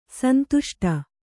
♪ suntuṣṭa